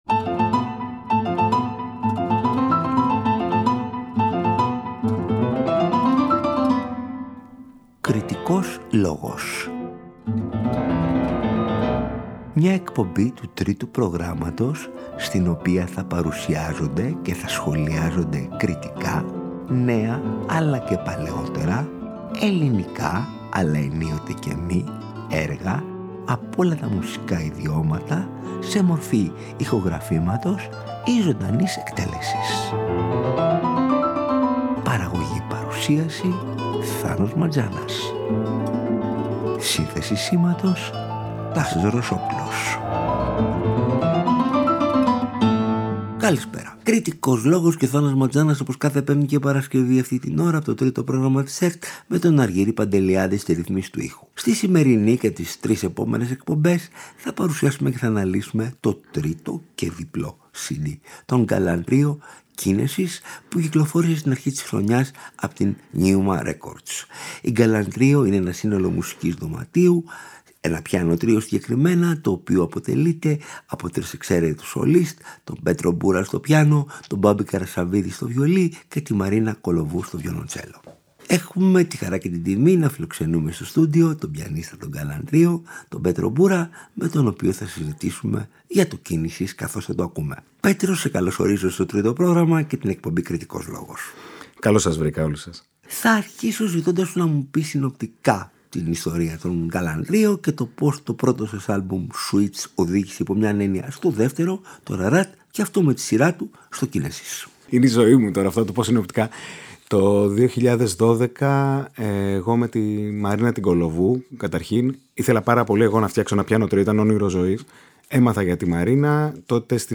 Διακρίνονται για την πολύ μεγάλη μεταξύ τους υφολογική και στιλιστική ευρύτητα, από πιάνο τρίο της σπουδαίας παράδοσης του ρομαντισμού και της προγραμματικής μουσικής μέχρι τις πιο σύγχρονες τάσεις και την ατονικότητα.